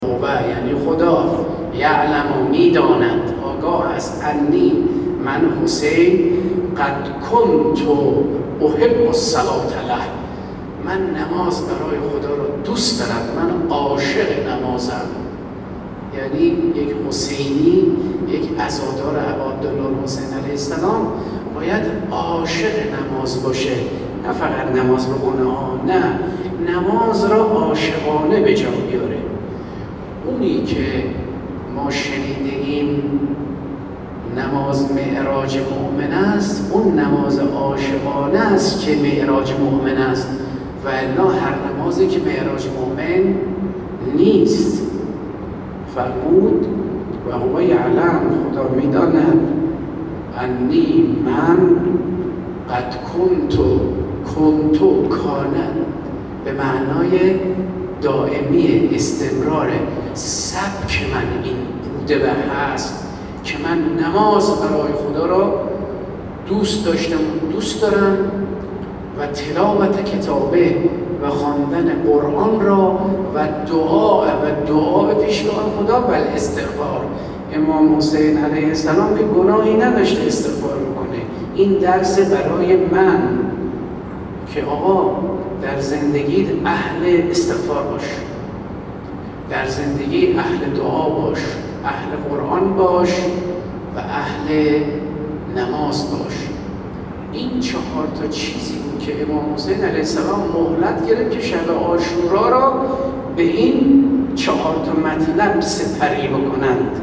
به گزارش خبرنگار ایکنا، حجت‌الاسلام والمسلمین عباس محمدحسنی، نماینده ولی‌فقیه در ارتش و رئیس سازمان عقیدتی ـ سیاسی ارتش امروز، 28 تیر در آیین اختتامیه جشنواره سراسری تنویر (خانه‌های قرآنی) ارتش طی سخنانی گفت: توفیق شرکت در این مراسم، قطعاً کششی از سوی خداوند و قرآن بوده است و نشان‌دهنده لطف خدا به افرادی است که در مسیر خدا گام برمی‌دارند.